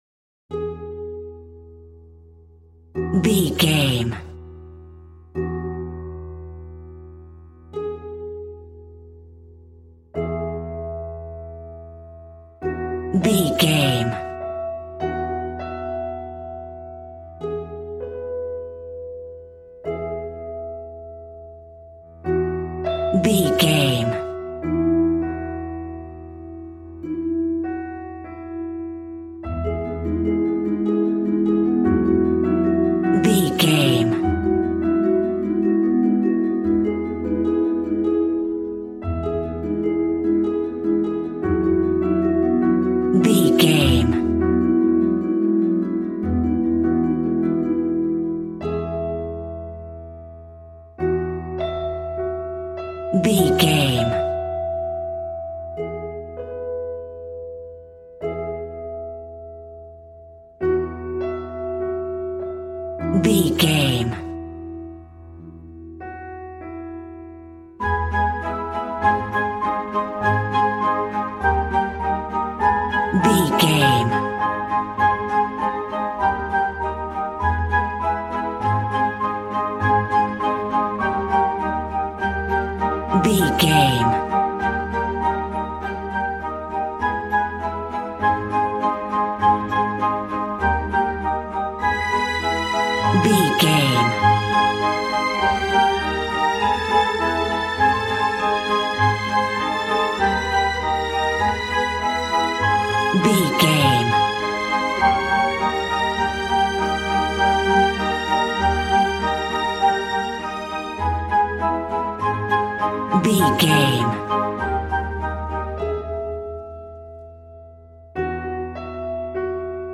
Regal and romantic, a classy piece of classical music.
Aeolian/Minor
A♭
regal
strings
violin
brass